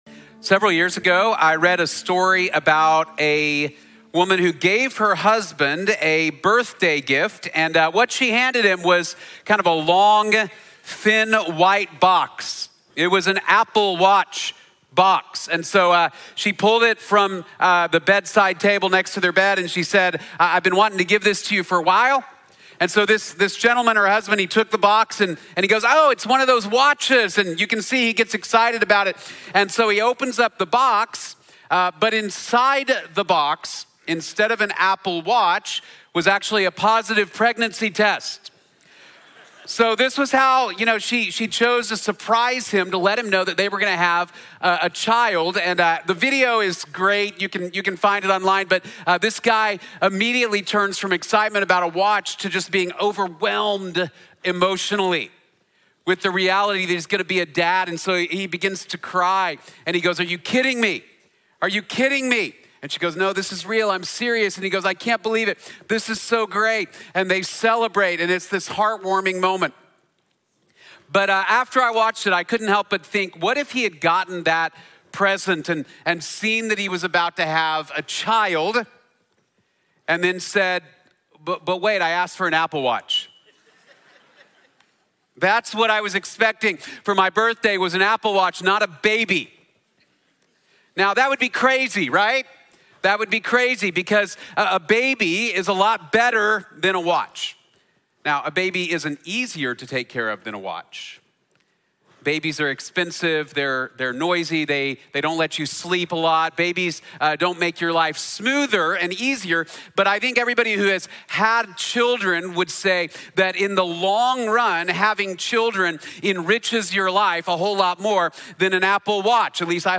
God’s Plans Are Better | Sermon | Grace Bible Church